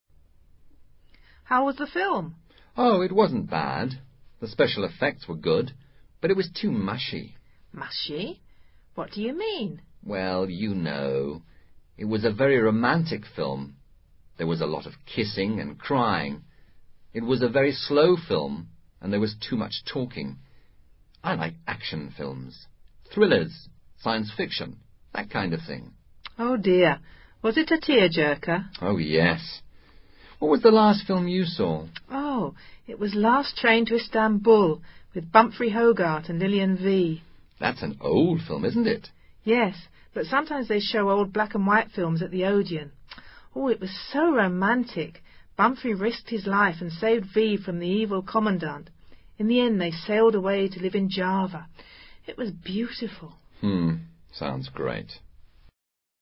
Dos personajes conversan sobre una película.